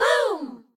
rahRahSisBoomBaBoomgirls6.ogg